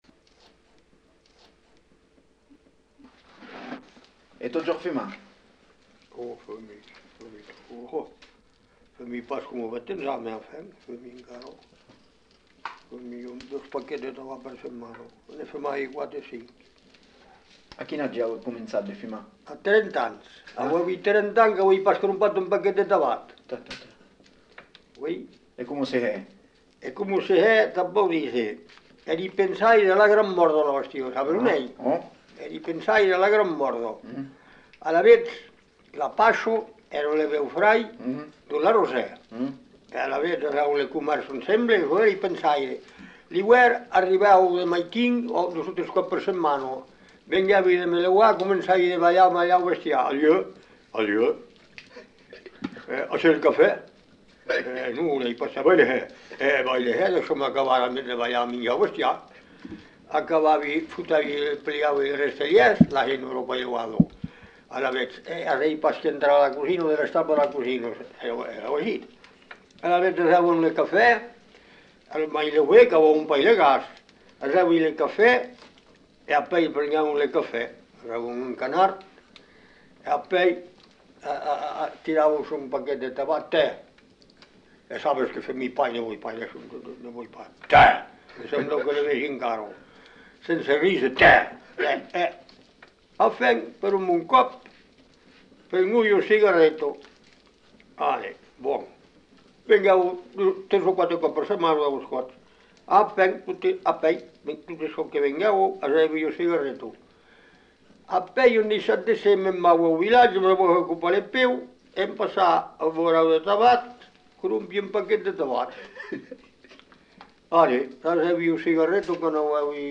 Aire culturelle : Savès
Genre : récit de vie